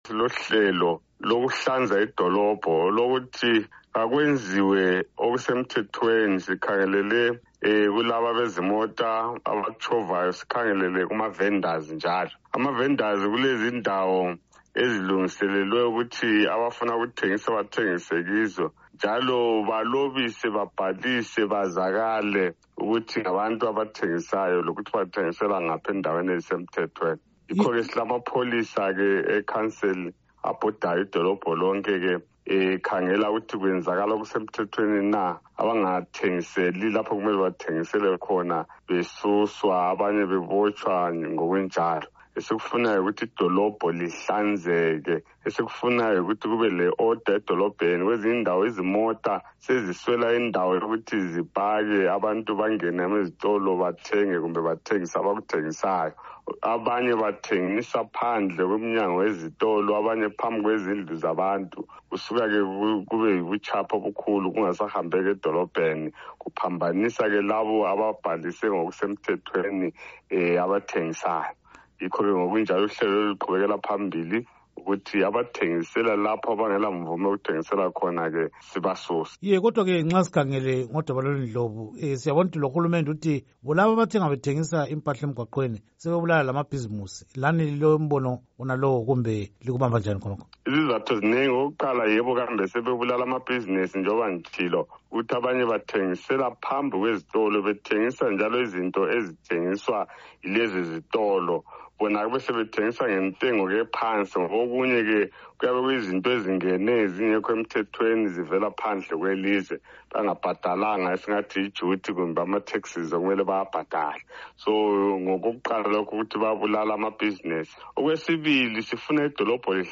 Ingxoxo loMnu Edwin Ndlovu